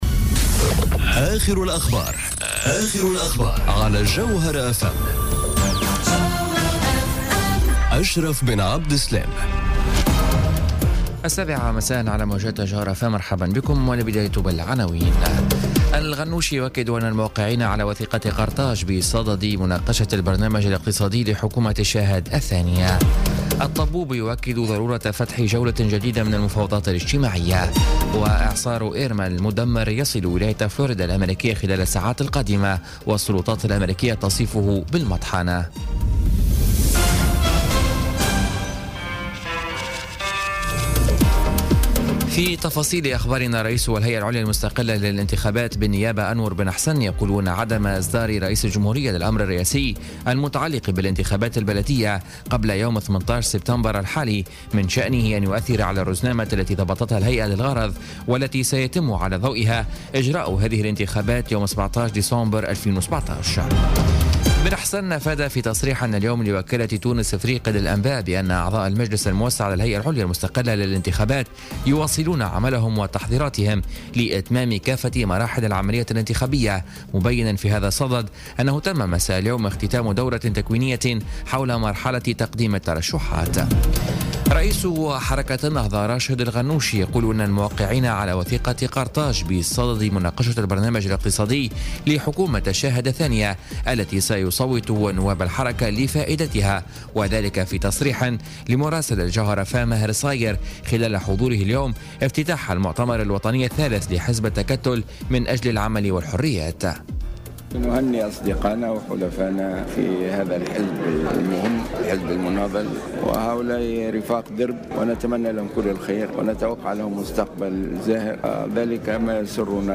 نشرة أخبار السابعة مساء ليوم السبت 9 سبتمبر 2017